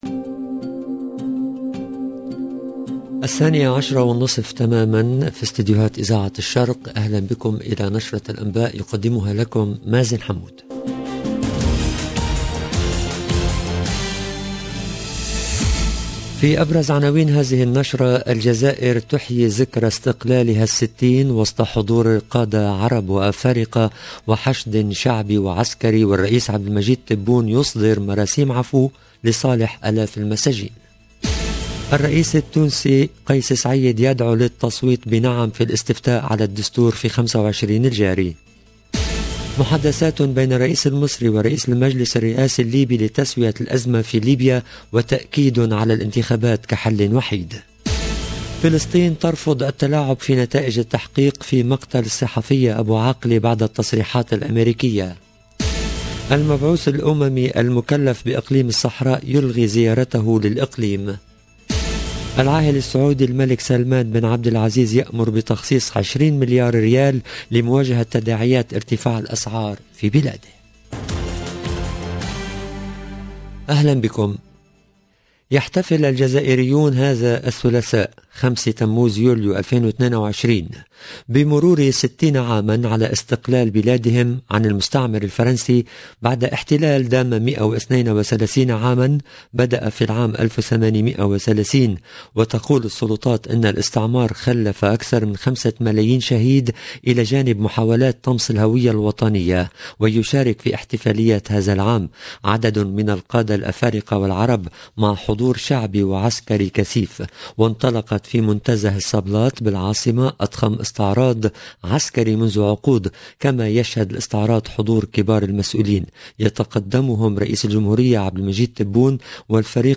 LE JOURNAL DE MIDI 30 EN LANGUE ARABE DU 5/07/22